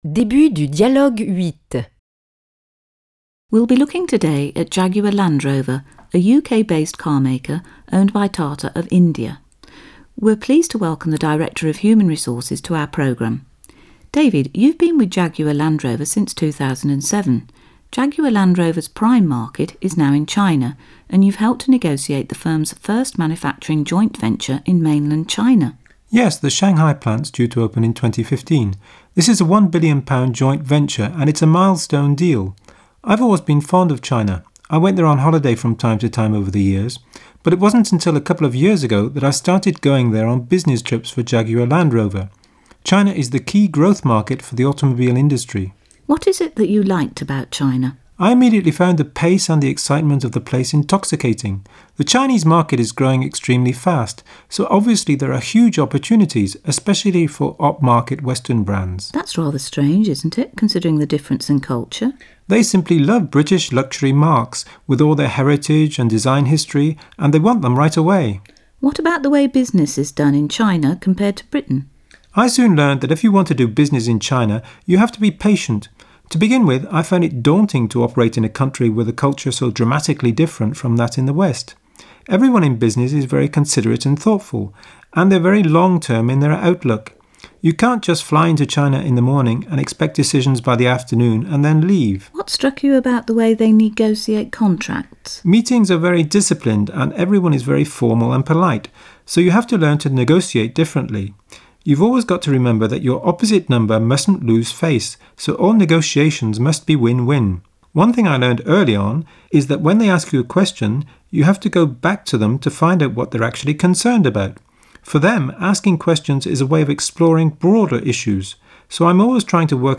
L'épreuve se déroule à partir de l'écoute d'un dialogue (env. 4 mn) portant sur un sujet général ou socio-économique.